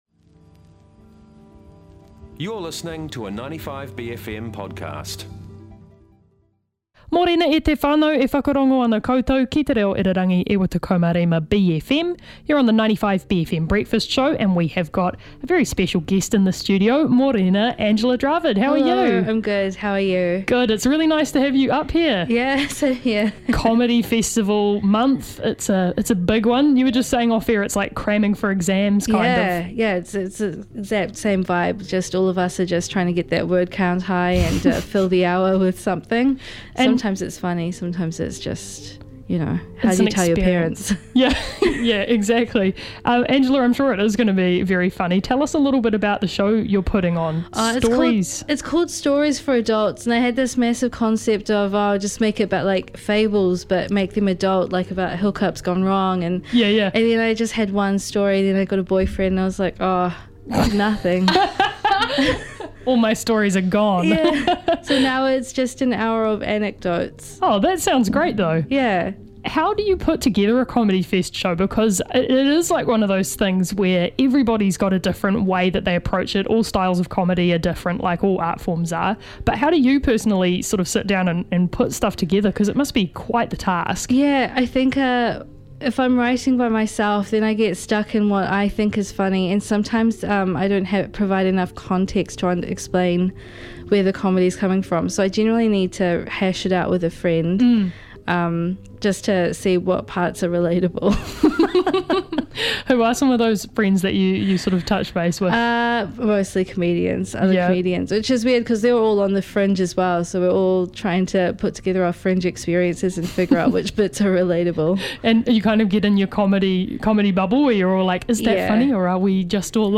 The NZ International Comedy Festival is kicking off next week, and Angella Dravid popped in to chat about her show 'Stories for Adults'.